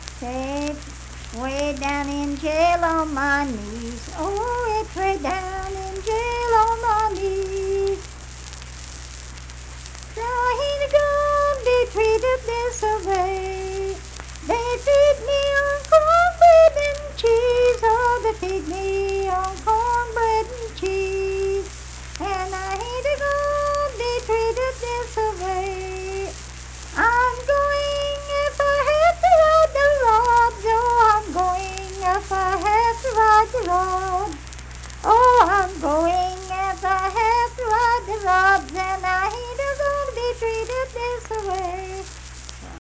Unaccompanied vocal music performance
Verse-refrain 3(3-4).
Voice (sung)